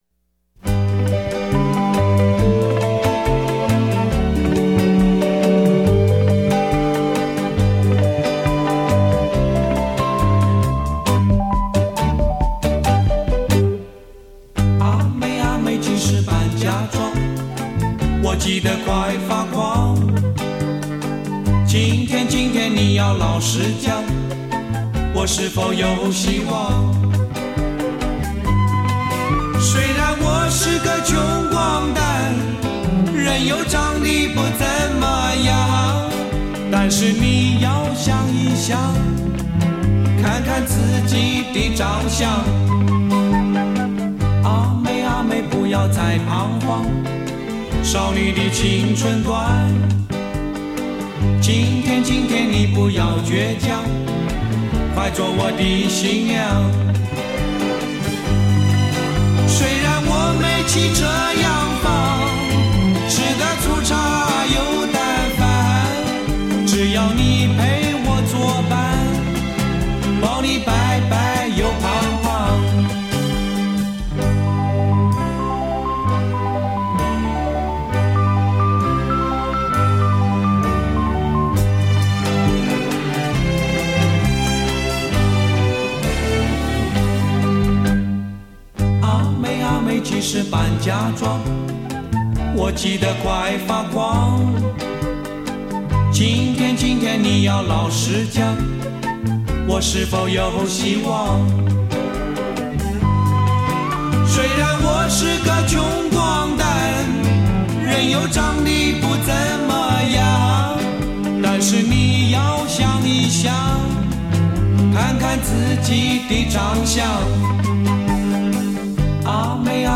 音乐结构短小精悍，旋律简洁朴实、清新爽朗，具有浓郁的乡土气息和时代感。